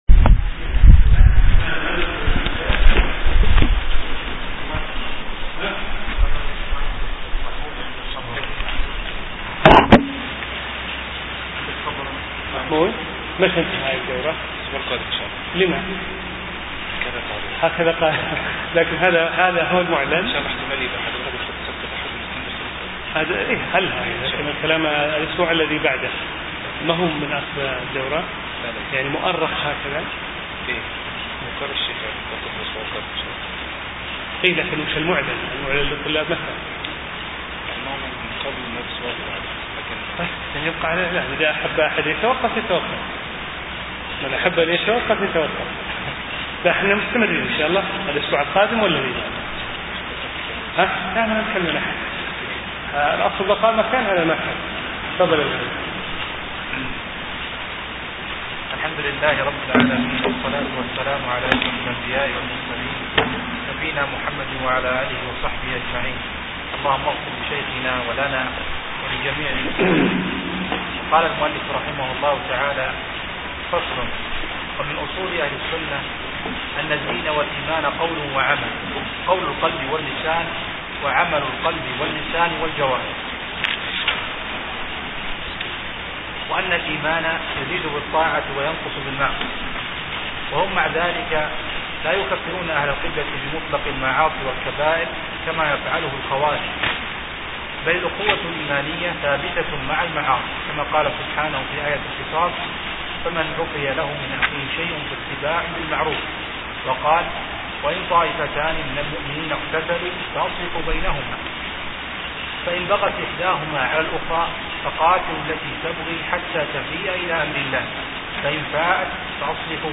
شرح العقيدة الواسطية لابن تيمية